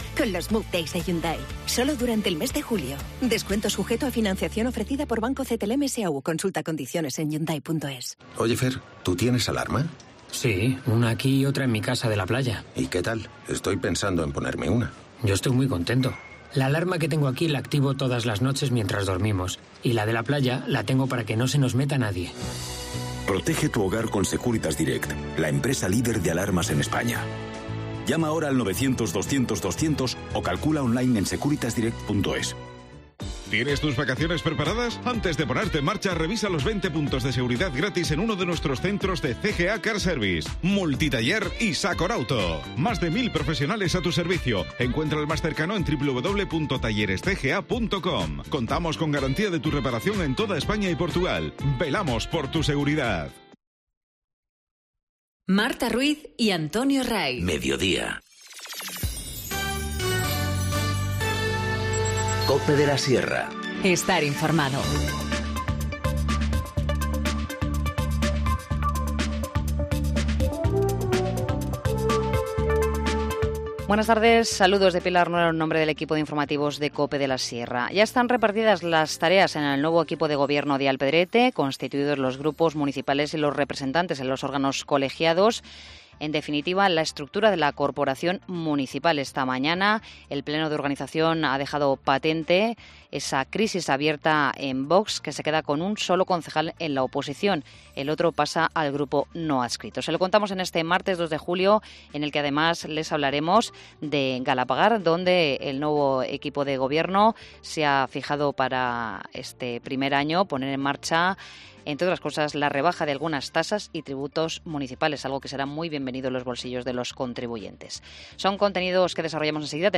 Informativo Mediodía 2 julio 14:20h